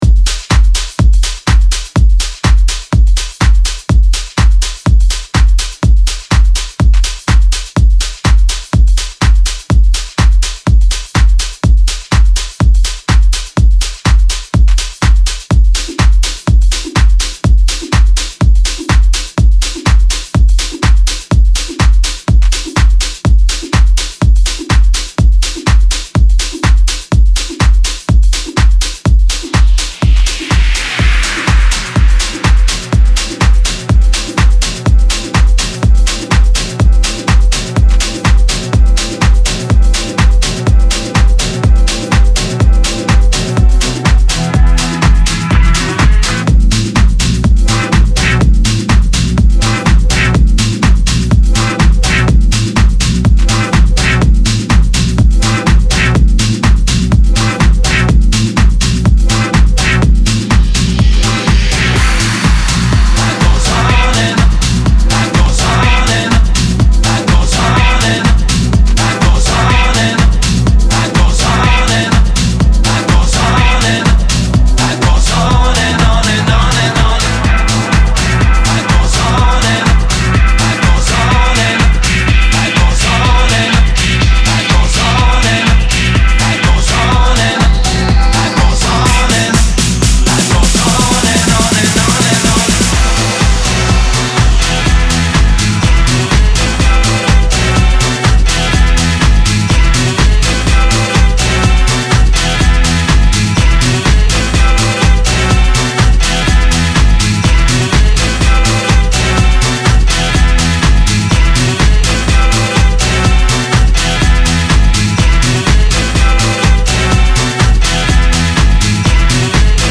House Dub Remix